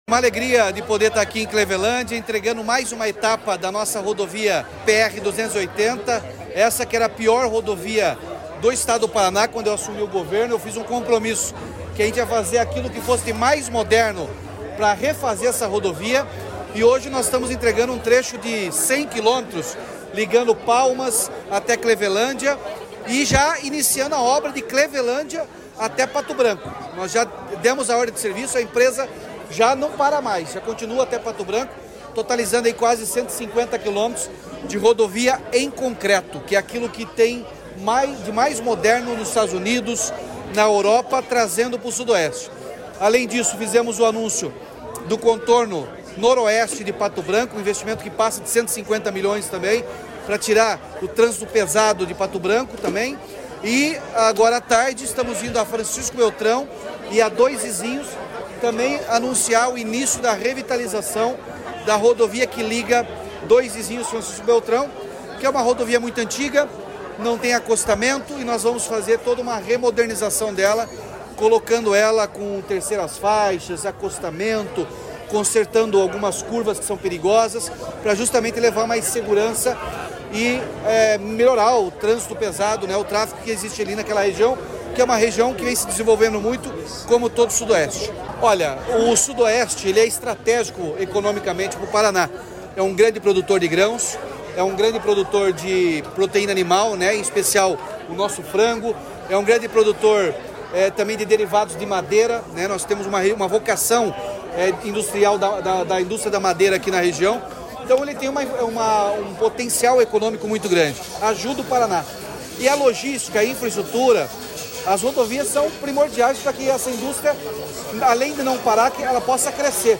Sonora do governador Ratinho Junior sobre a inauguração do segundo trecho da PRC-280 entre Palmas e Clevelândia